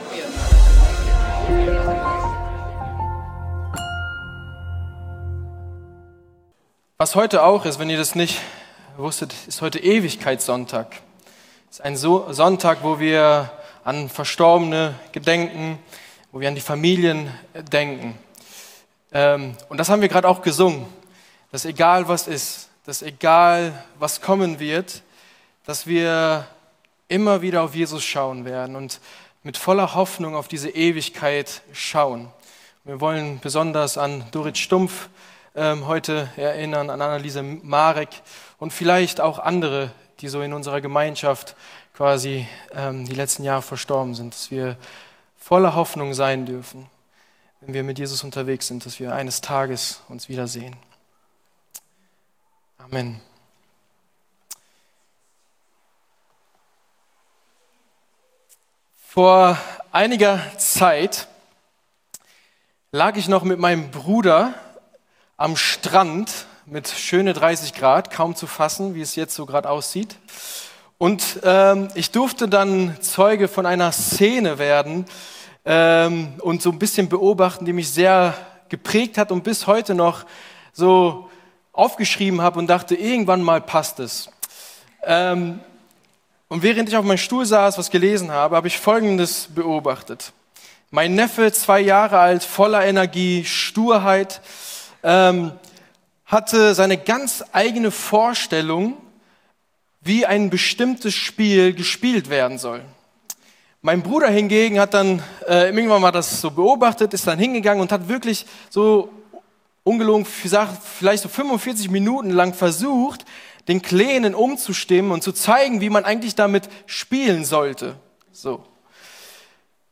- Predigten der LUKAS GEMEINDE - Podcast